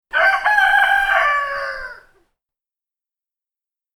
gallo.mp3